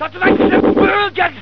Tetsu-maki-swish.wav